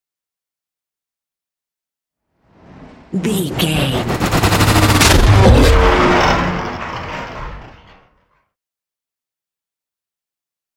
Sci fi whoosh to hit big
Sound Effects
Atonal
dark
driving
futuristic
intense
tension
woosh to hit